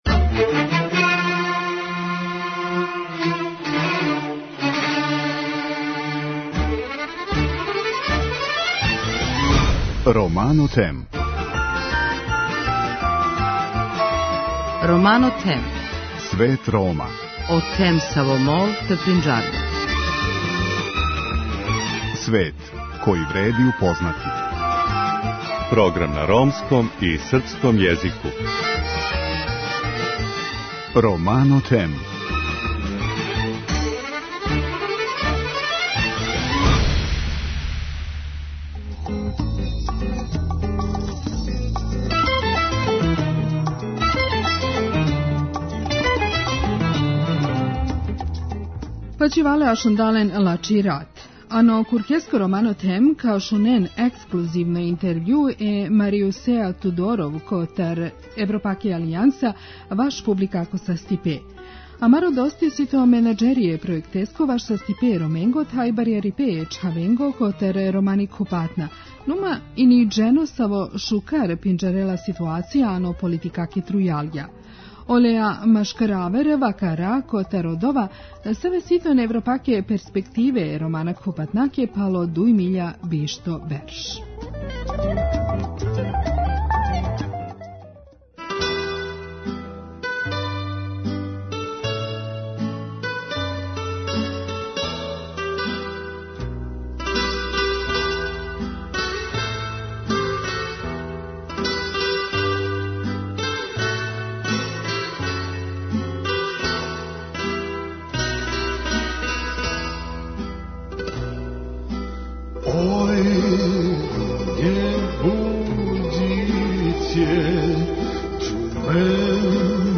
На ова и многа друга актуелна питања одговара наш вечерашњи гост.